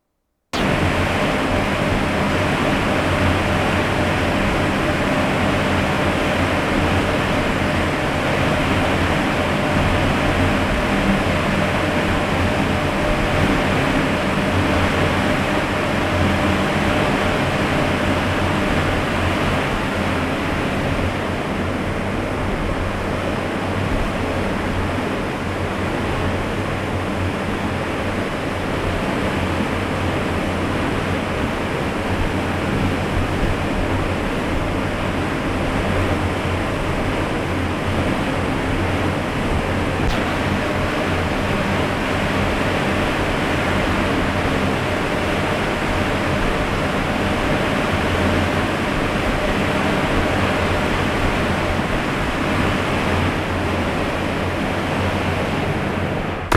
8.  BREWERY, INSIDE LOADING AREA
" , AIR CONDITIONER
14. Change of ambience and door shutting.